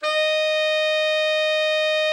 Index of /90_sSampleCDs/Giga Samples Collection/Sax/TENOR VEL-OB